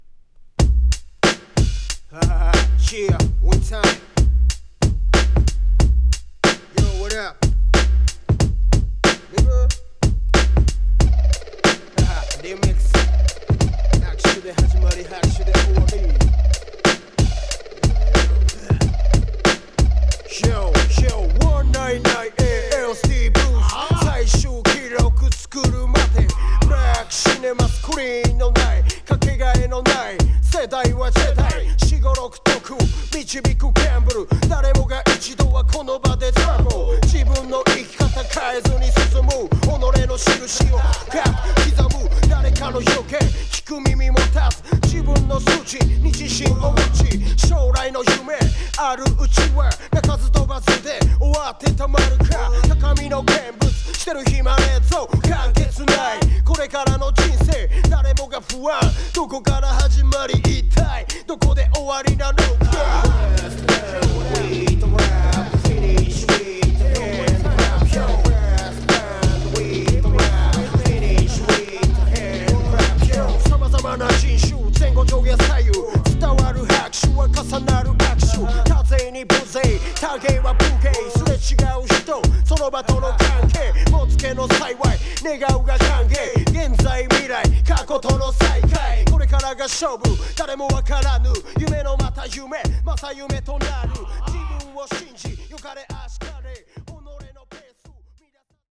2. > HIPHOP